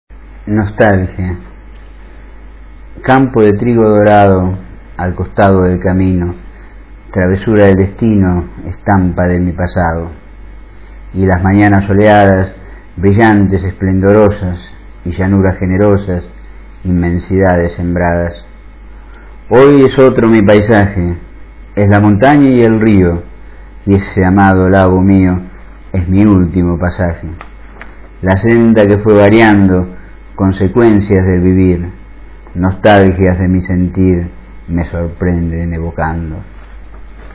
Recitado por el autor